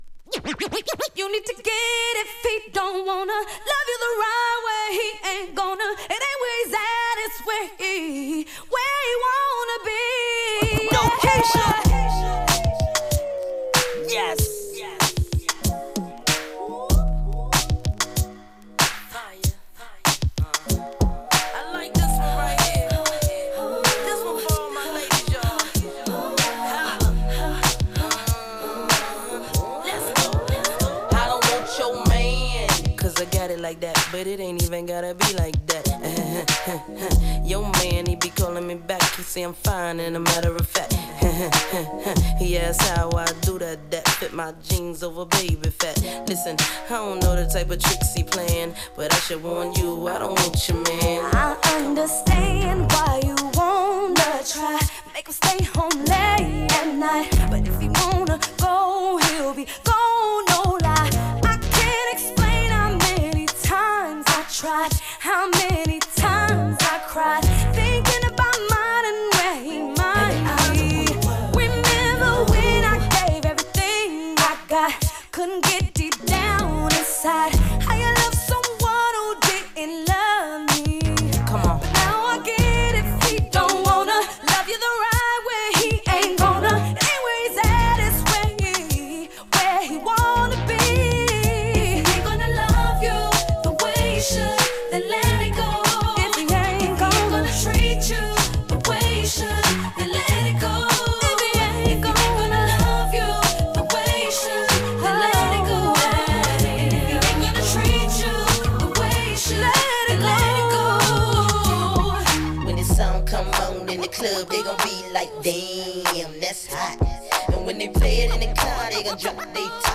> 2000's/MAINSTREAM